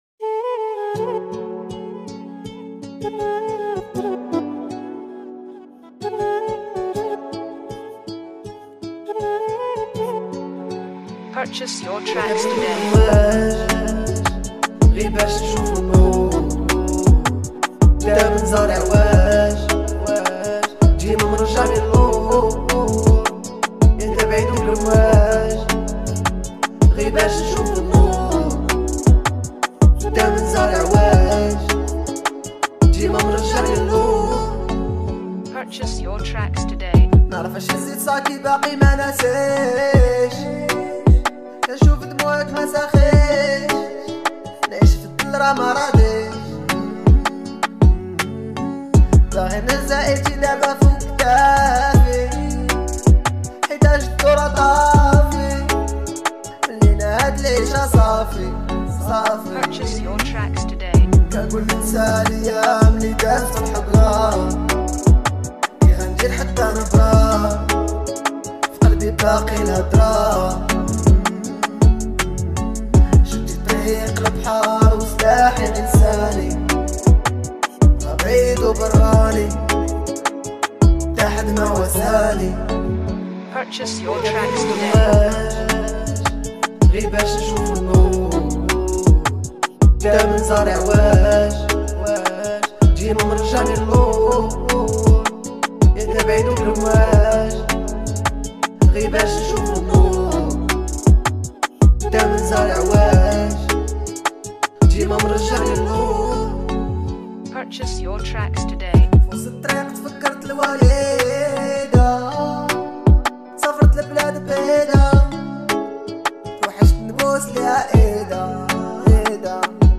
это завораживающий трек в жанре инди-поп
проникновенен и эмоционален